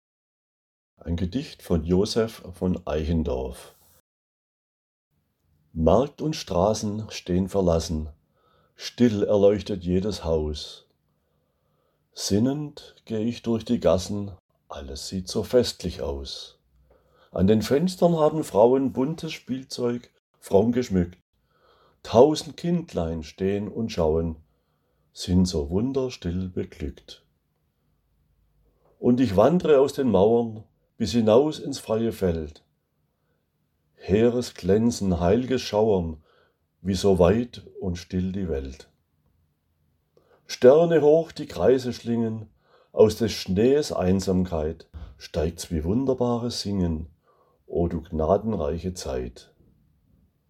Gedicht v. Joseph von Eichendorff